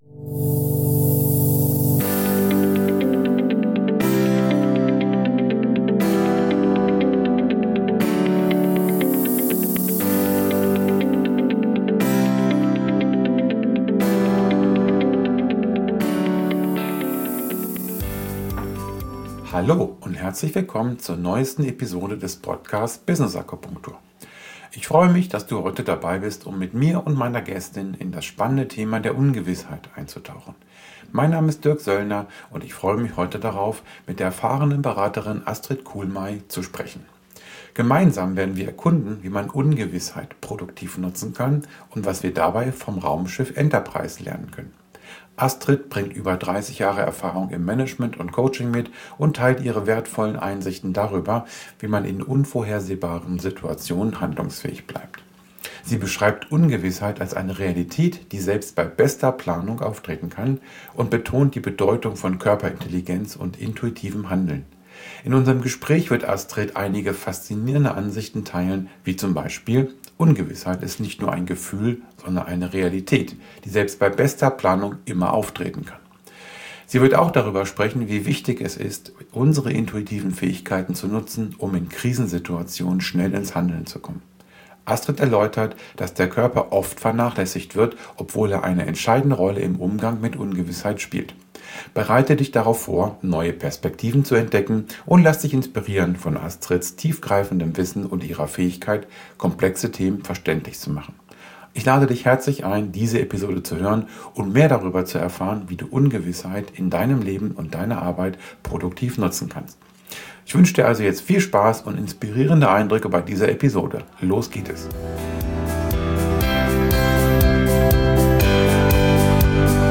Gespräch. Wir diskutieren, wie man Ungewissheit produktiv nutzen kann, inspiriert von der Serie "Raumschiff Enterprise".